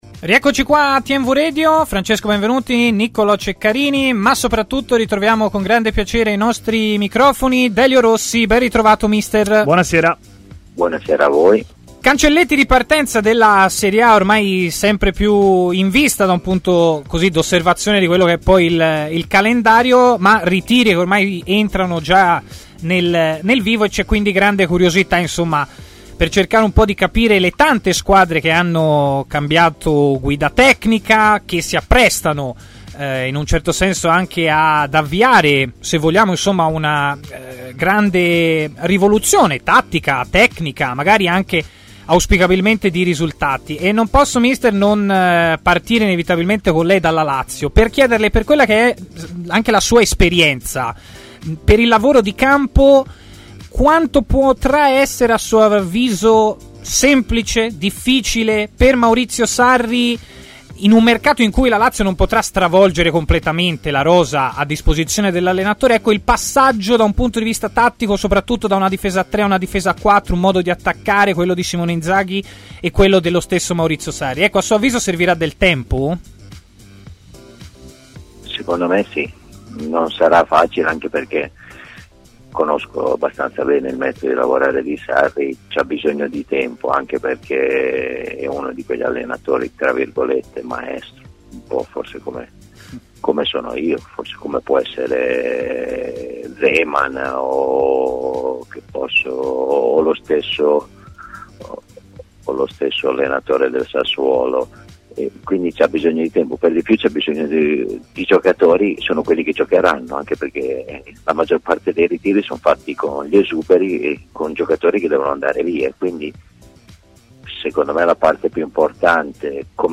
L’allenatore Delio Rossi ha così parlato ai microfoni di TMW Radio